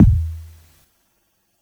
• Long Tail Reverb Steel Kick Drum Sample E Key 195.wav
Royality free bass drum sample tuned to the E note. Loudest frequency: 188Hz
long-tail-reverb-steel-kick-drum-sample-e-key-195-Dj6.wav